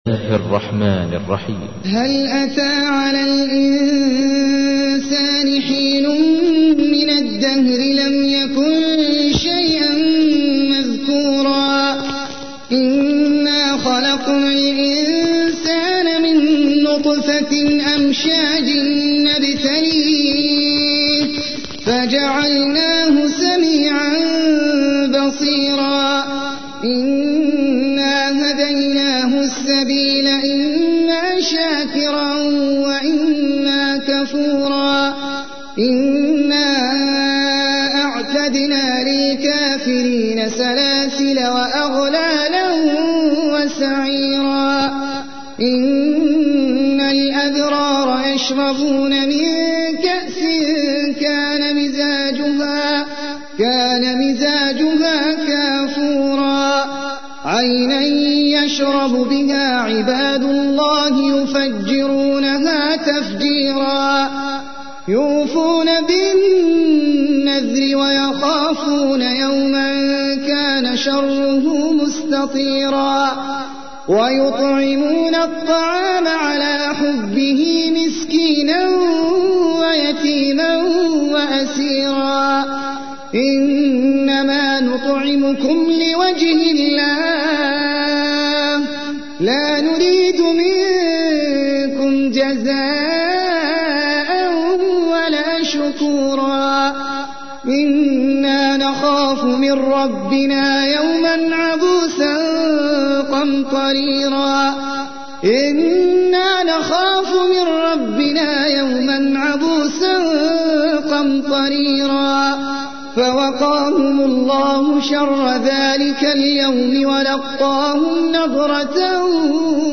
تحميل : 76. سورة الإنسان / القارئ احمد العجمي / القرآن الكريم / موقع يا حسين